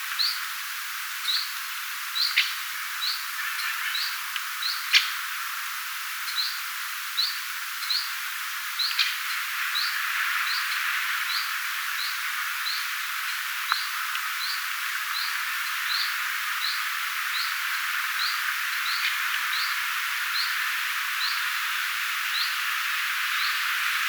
tuolla tavoin ääntelevä tiltalttilintu
tuolla_tavoin_aanteleva_tiltalttilintu.mp3